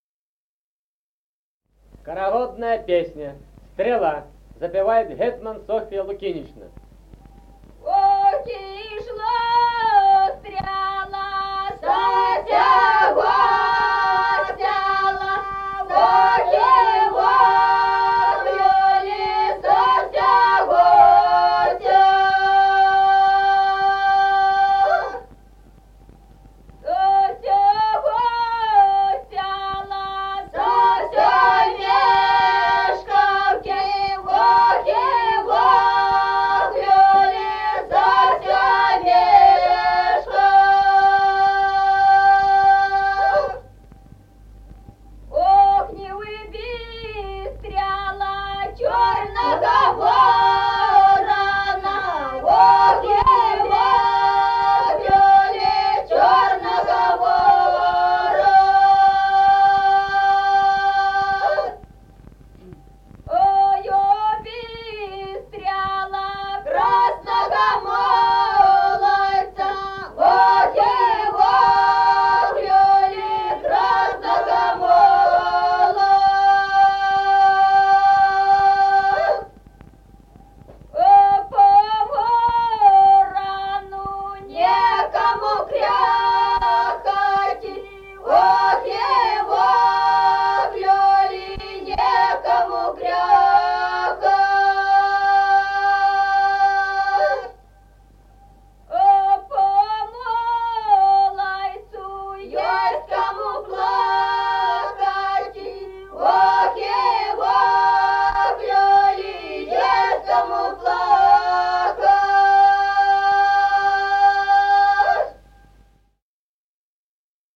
Народные песни Стародубского района «Ох, ишла стрела», юрьевская таночная.
1953 г., с. Мишковка.